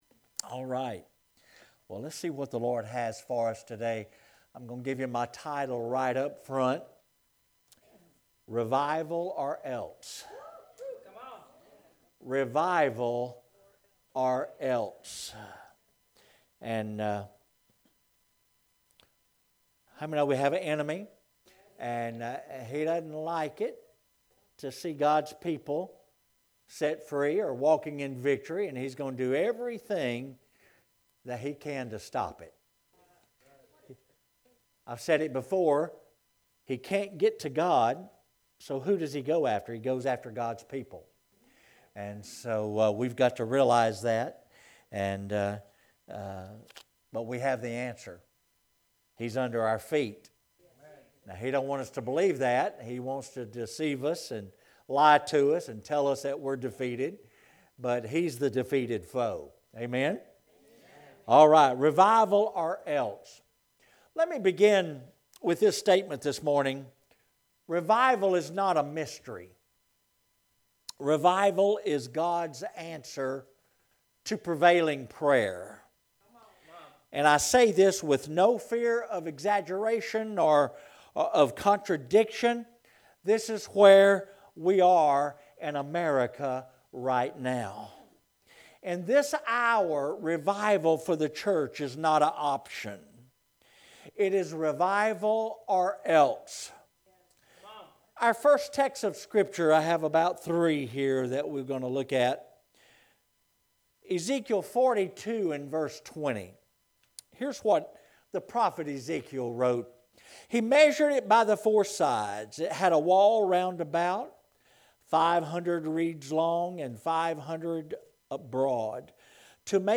Sermons | New Life Ministries